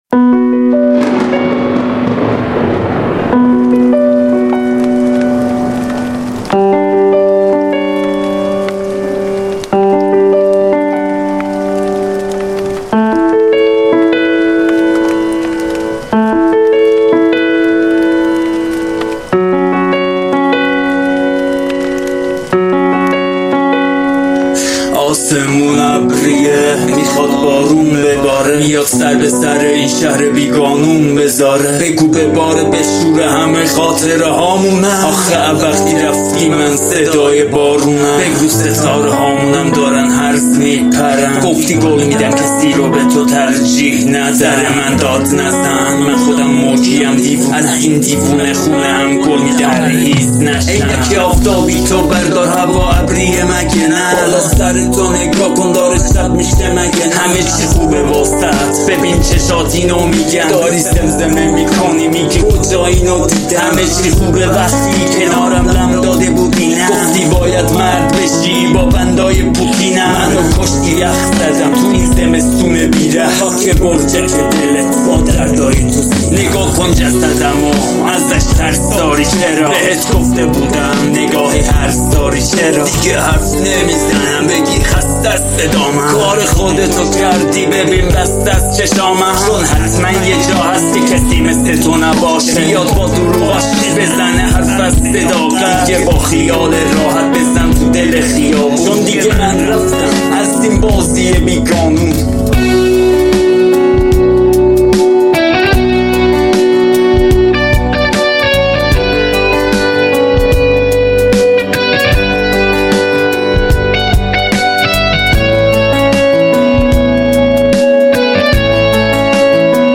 Genre: Rap & hip hop